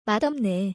マド